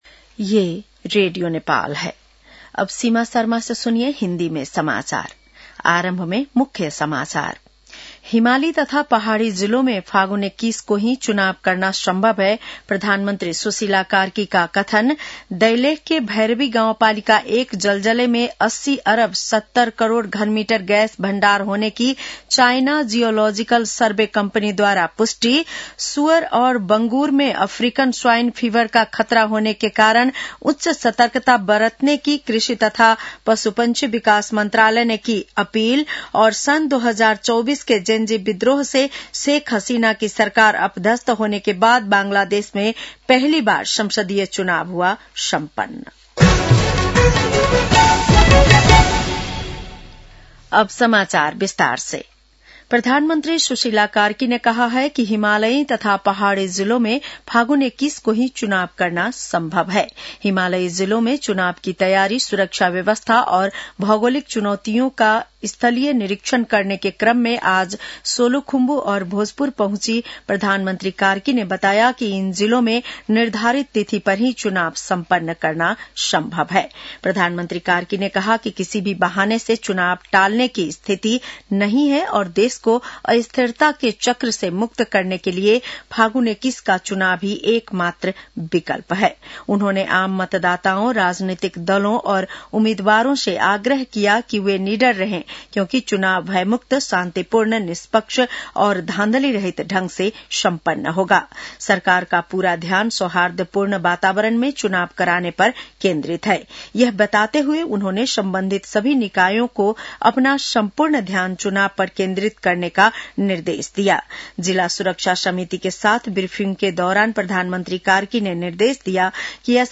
बेलुकी १० बजेको हिन्दी समाचार : २९ माघ , २०८२
10-pm-hindi-news-1-2.mp3